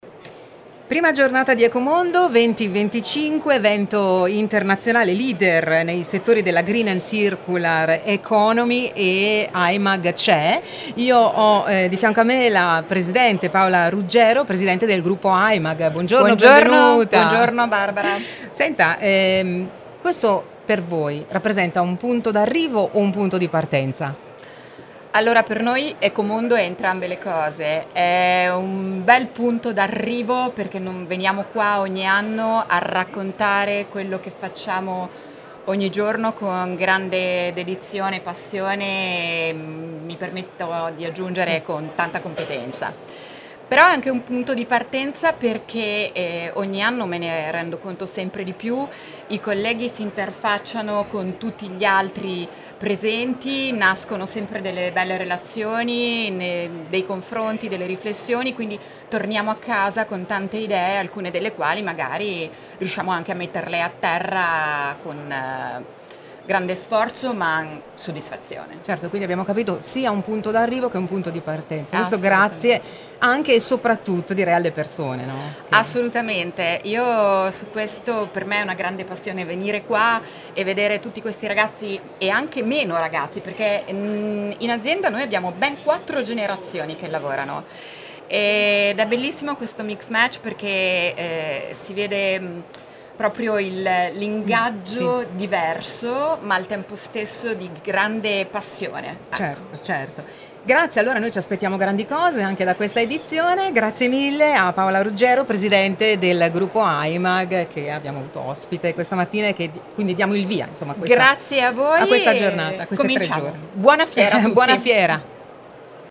Il Gruppo Aimag è alla Fiera di Rimini per Ecomondo, l’evento (4-7 novembre) di riferimento per la Green e la Circular Economy, con “Innovation on air” per un focus sull’innovazione al servizio della transizione ecologica.